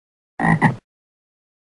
دانلود آهنگ قورباغه 1 از افکت صوتی انسان و موجودات زنده
دانلود صدای قورباغه از ساعد نیوز با لینک مستقیم و کیفیت بالا
جلوه های صوتی